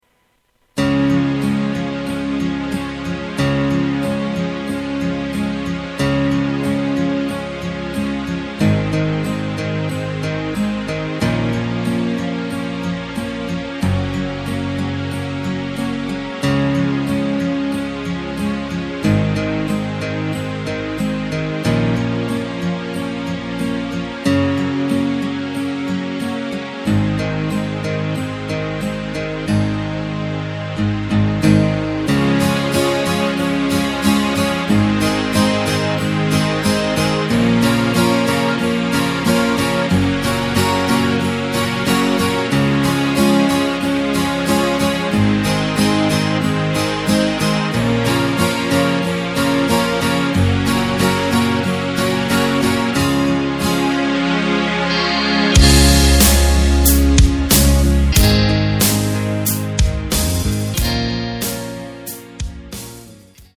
Tempo:         92.00
Tonart:            C#
Austropop aus dem Jahr 2020!